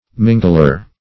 mingler - definition of mingler - synonyms, pronunciation, spelling from Free Dictionary Search Result for " mingler" : The Collaborative International Dictionary of English v.0.48: Mingler \Min"gler\, n. One who mingles.